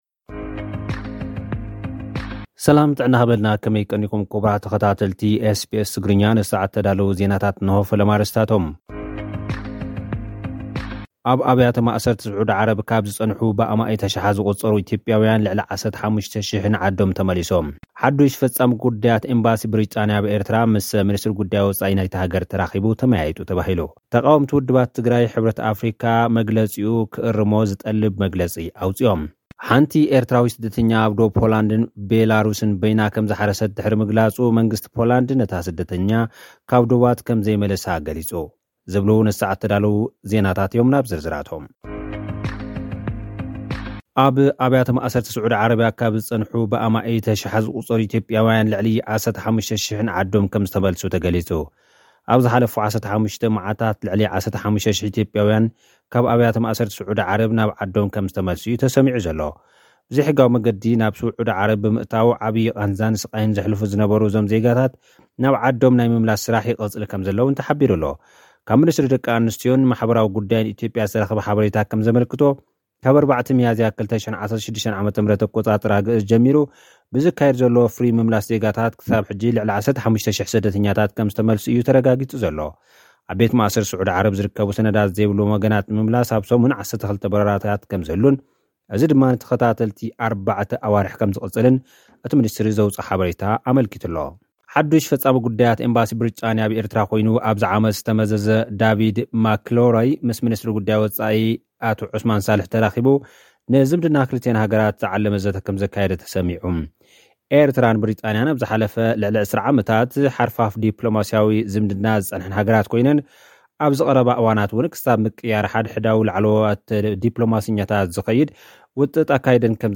ተቓወምቲ ውድባት ትግራይ ሕብረት ኣፍሪቃ መግለጺኡ ክእርም ዝጠልብ መግለጺ ኣውጺኦም። (ጸብጻብ)